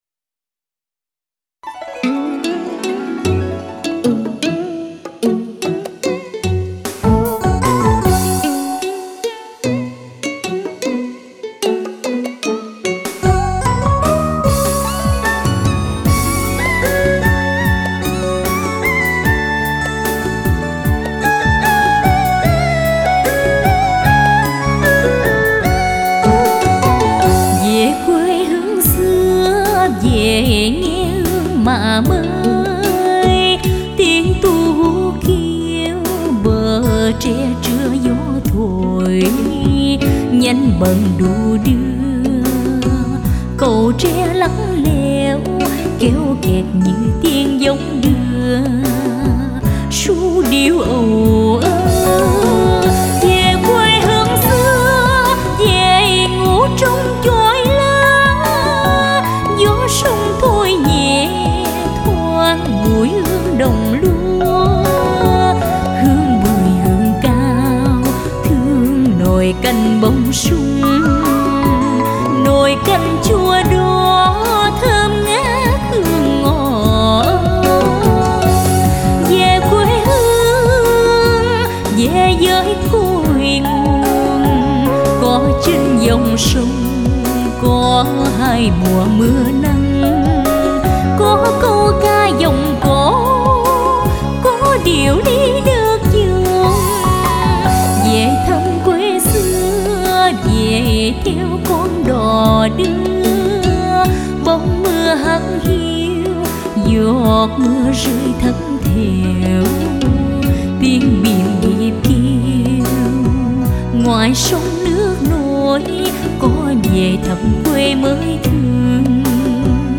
Tiếng hát